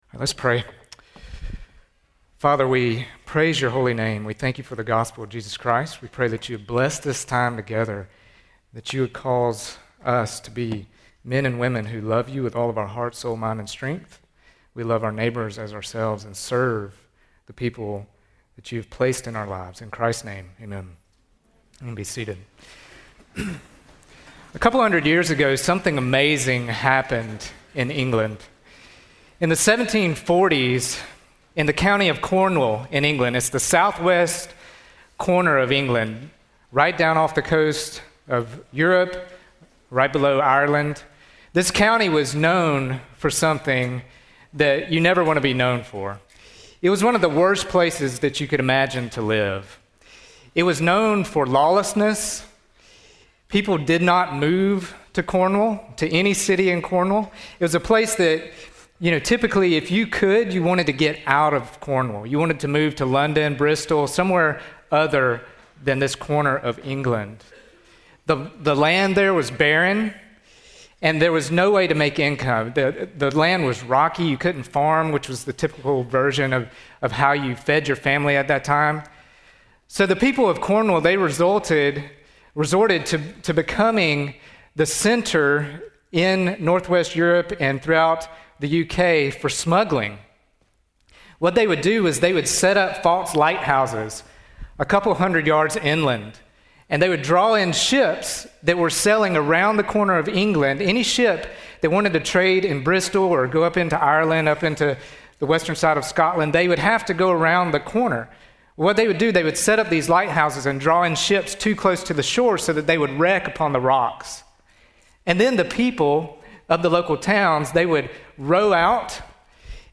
GO Week Chapel